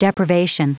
Prononciation du mot deprivation en anglais (fichier audio)
Prononciation du mot : deprivation